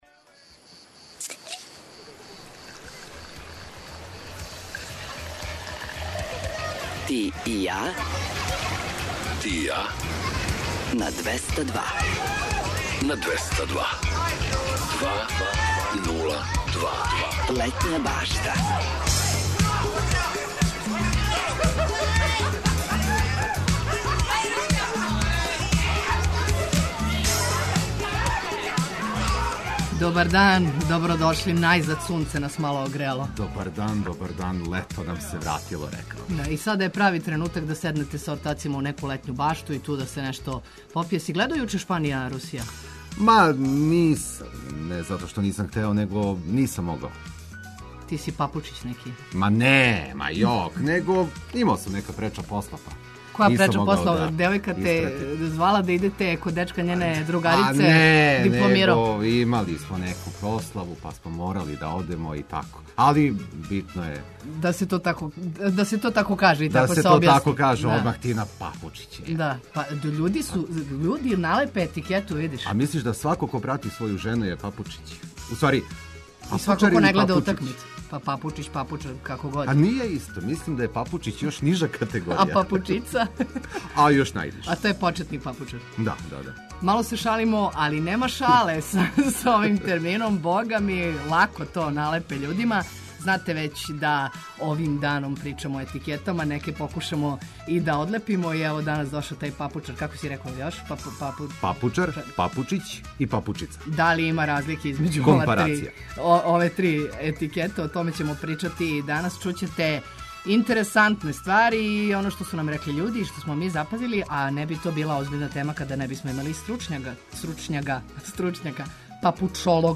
Биће речи о бесплатним уметничким радионицама током јула и августа у „Пароброду", наравно, све зачињено сјајном музиком и нашим редовним вестима.